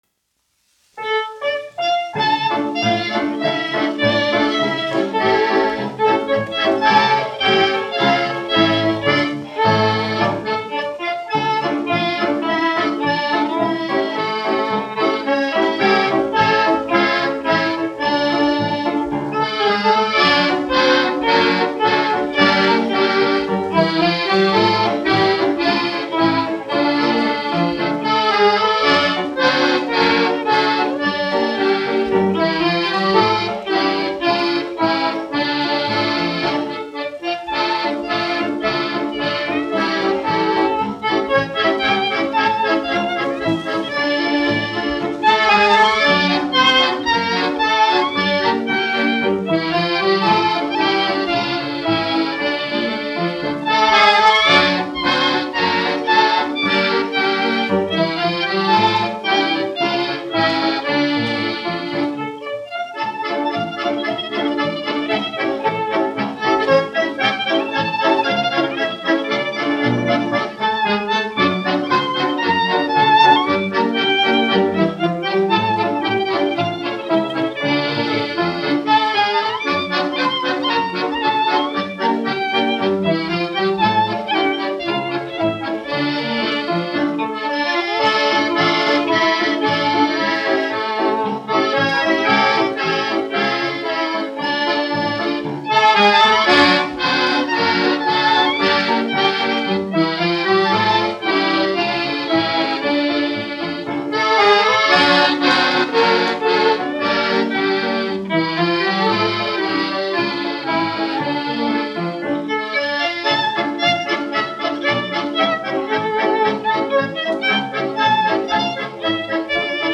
1 skpl. : analogs, 78 apgr/min, mono ; 25 cm
Fokstroti
Latvijas vēsturiskie šellaka skaņuplašu ieraksti (Kolekcija)